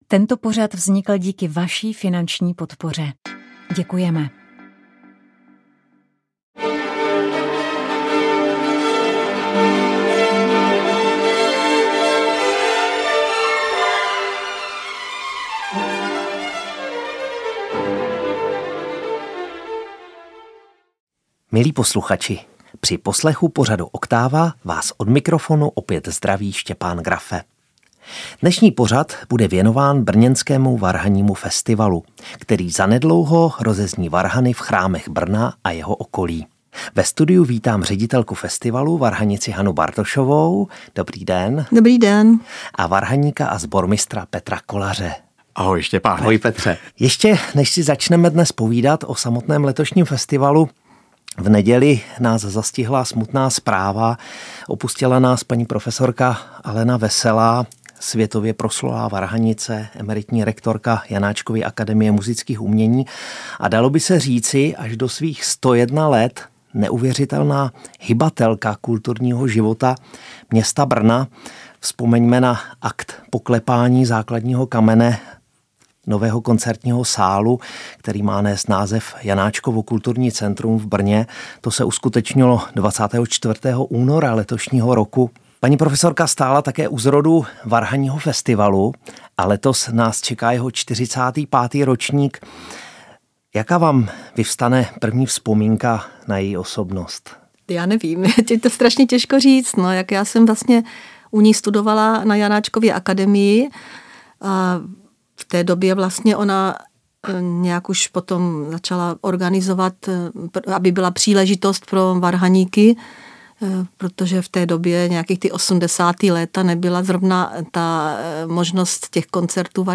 A o tom všem bude řeč v našem rozhovoru. I o tom, že CD, ze kterého uslyšíte hudební ukázky, se dostalo do Rádia Vatikán.